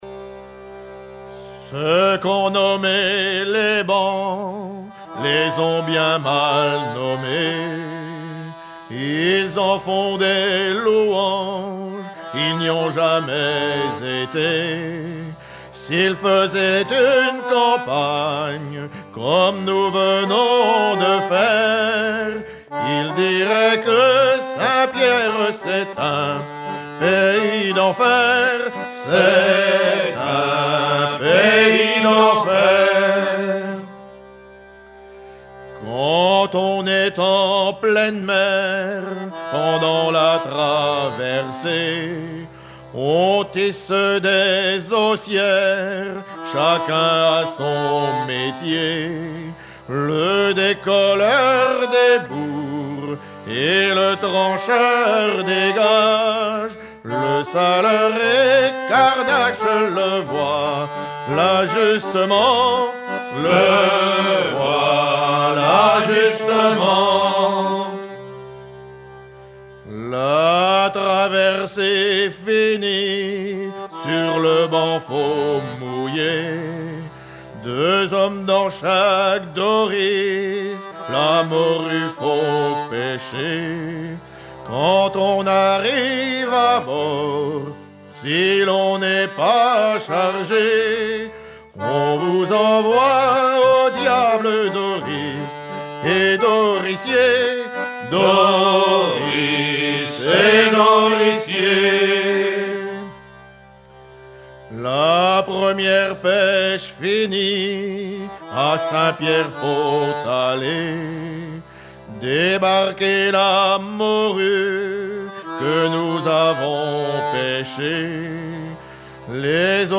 Version recueillie en 1976
Pièce musicale éditée